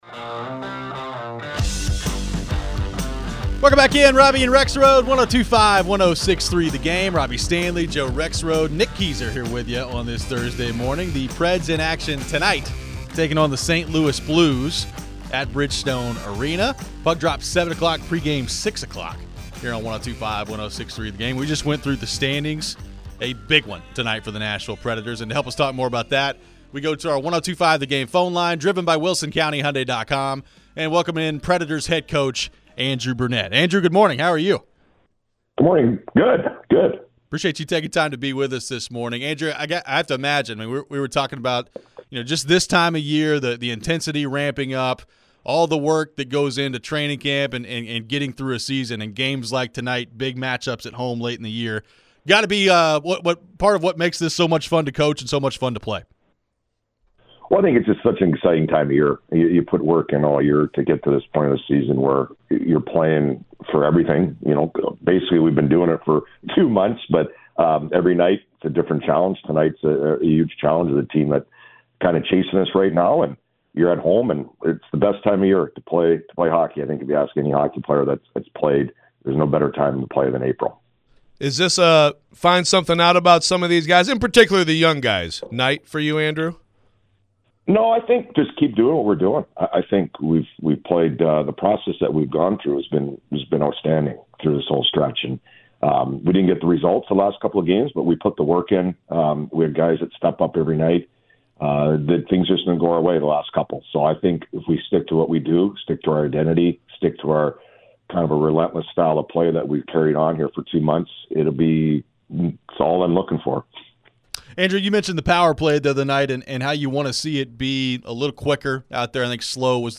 Andrew Brunette Interview (4-4-24)
Nashville Predators head coach, Andrew Brunette, joined the show ahead of their big game tonight against the St. Louis Blues. He shed some light on Filip Forsberg not practicing.